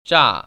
기호로만 보면 분명 이지만, 실제로 중국인들은 에 가깝게 발음합니다.
[()의 중국어 발음]